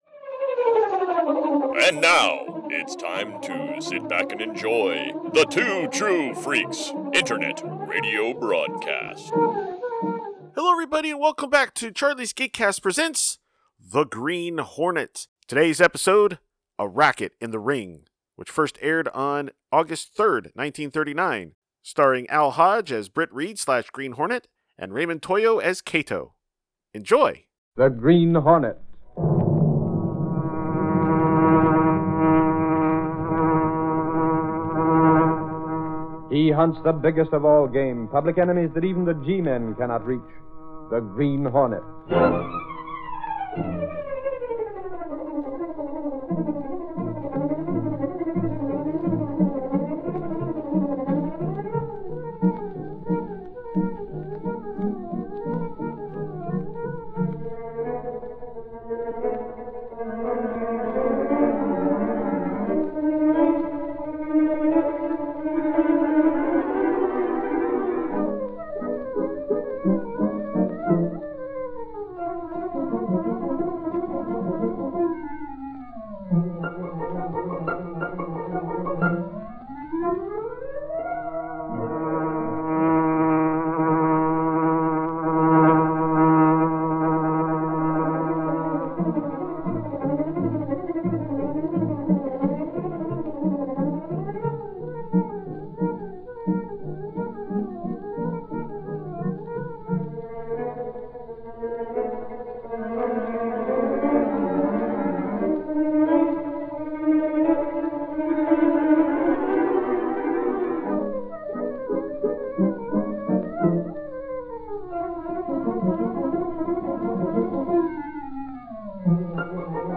See author's posts Tagged as: Kato , The Daily Sentinel , The Black Beauty , radio series , The Green Hornet , Britt Reid . email Rate it 1 2 3 4 5